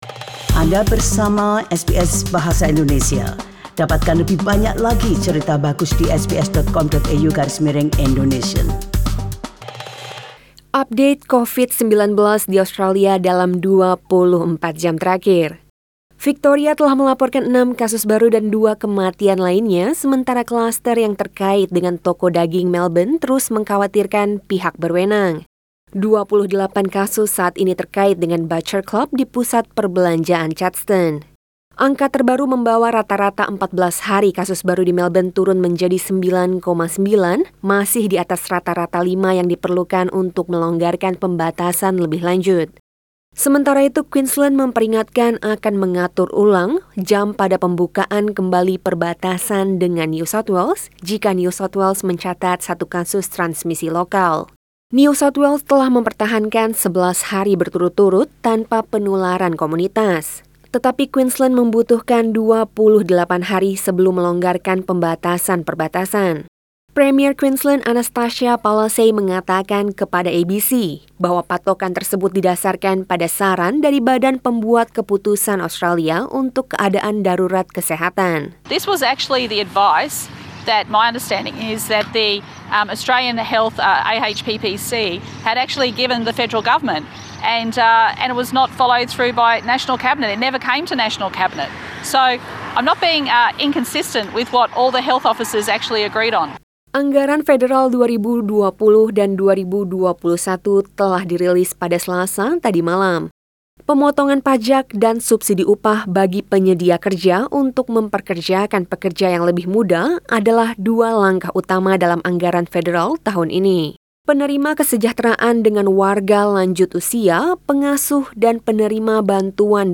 SBS Radio News in Bahasa Indonesia - 7 October 2020